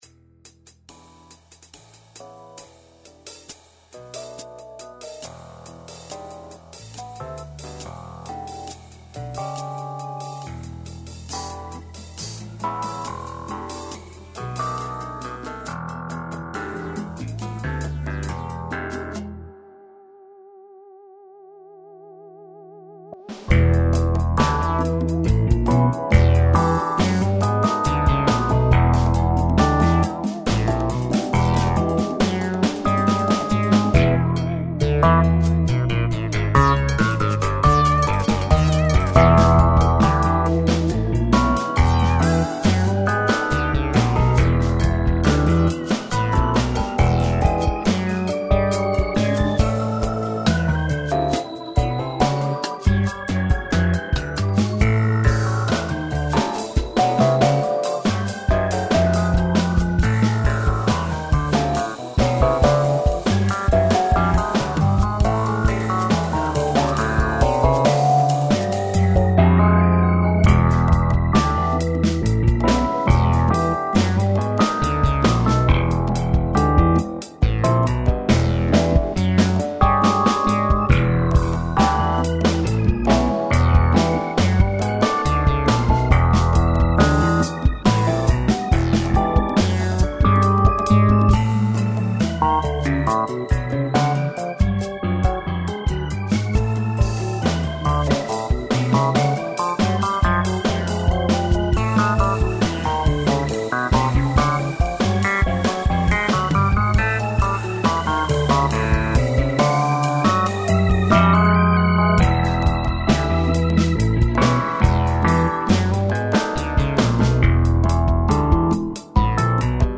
__________a 12/8 math funk tune